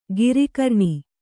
♪ giri karṇi